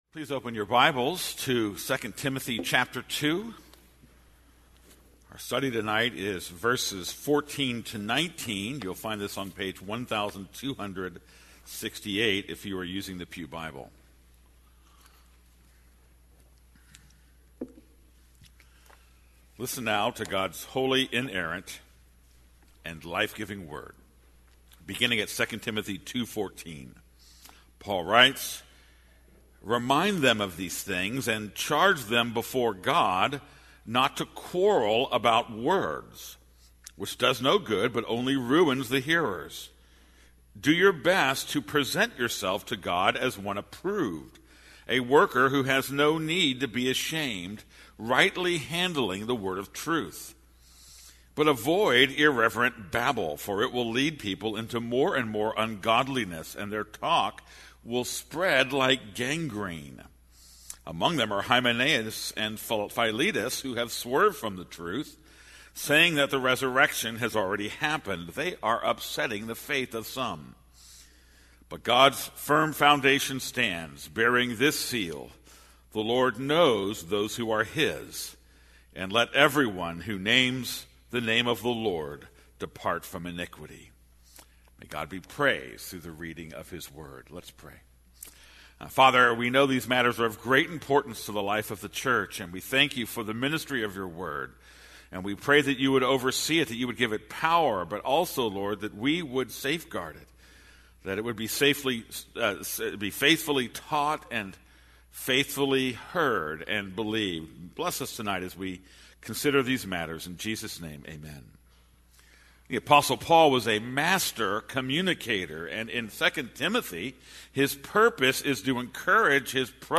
This is a sermon on 2 Timothy 2:14-21.